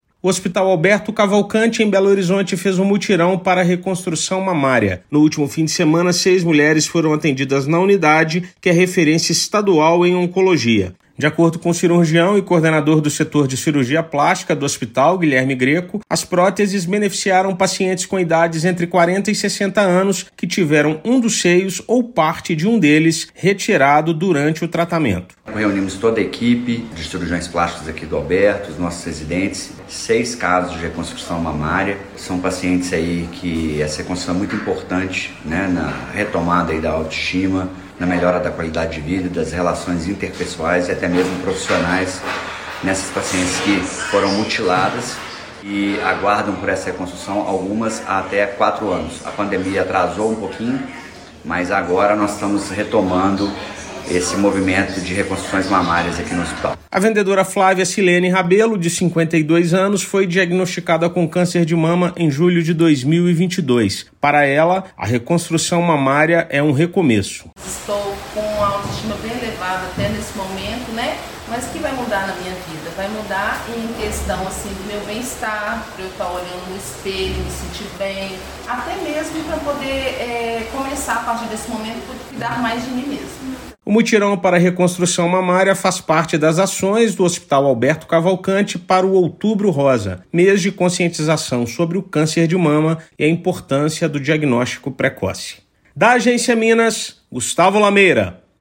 A ação integra o Outubro Rosa – mês de conscientização sobre o câncer de mama. Ouça matéria de rádio.